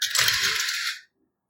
【効果音】切符を自動改札機に入れる音 - ポケットサウンド - フリー効果音素材・BGMダウンロード